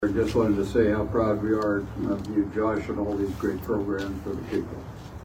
Manhattan Area Habitat for Humanity holds ribbon cutting ceremony for local family
State representative Mike Dodson also spoke briefly.